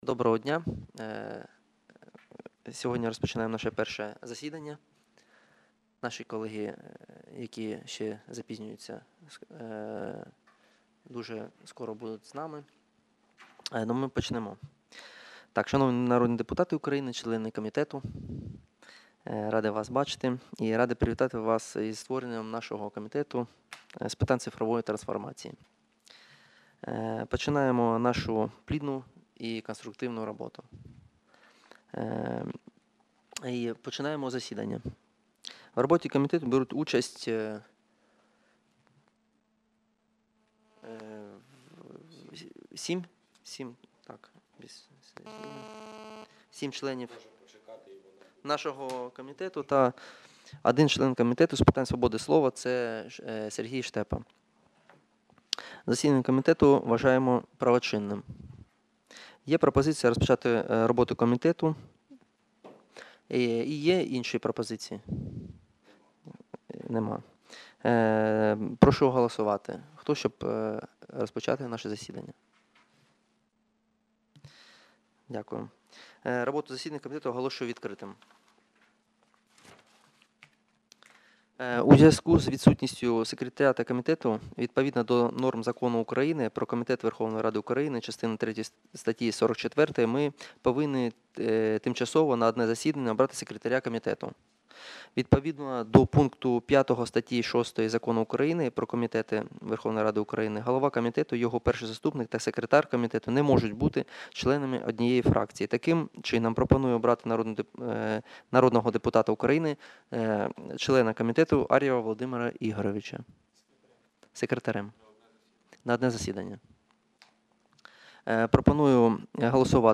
Аудіозапис засідання Комітету від 30.08.2019